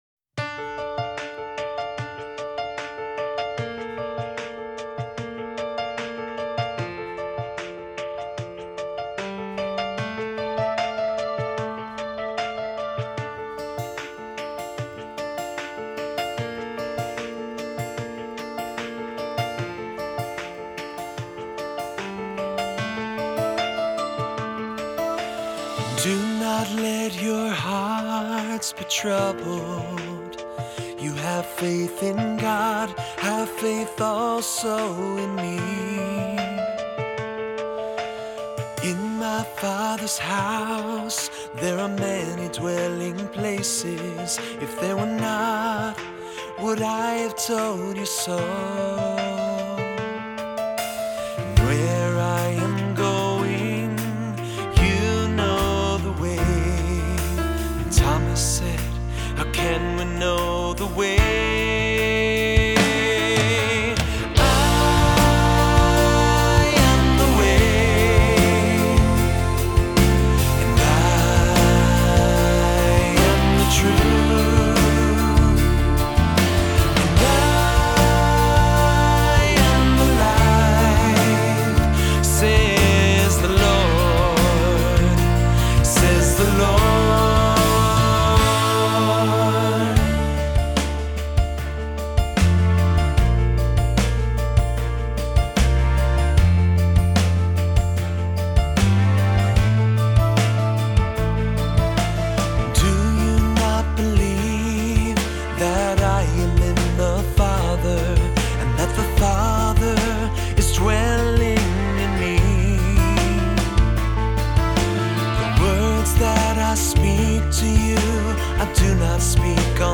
Voicing: 3-part Choir, assembly, cantor, descant